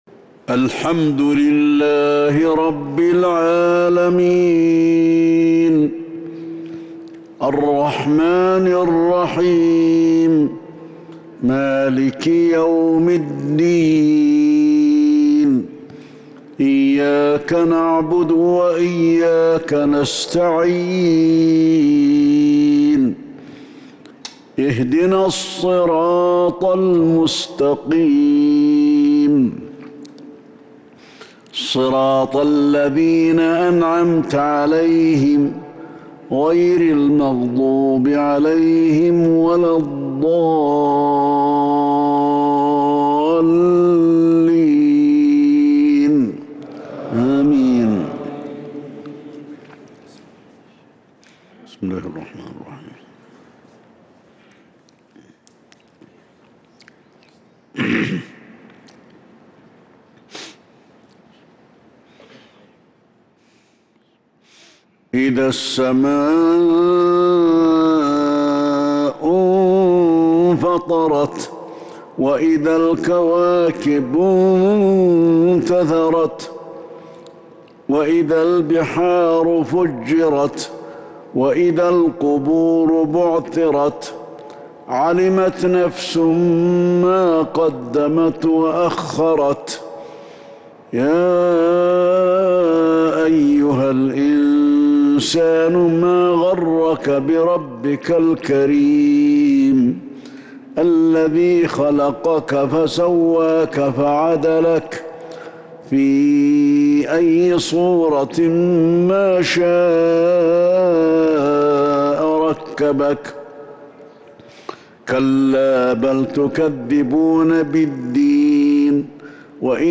صلاة المغرب 8-9-1442هـ سورتي الإنفطار والعصر| Maghrib prayer Surah Al-Infitar and Al-‘Asr 20/4/2021 > 1442 🕌 > الفروض - تلاوات الحرمين